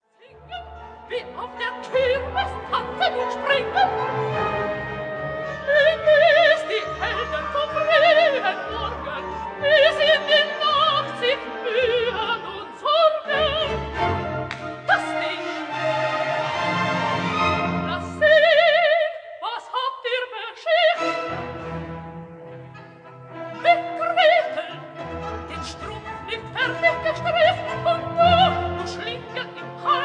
soprano
contralto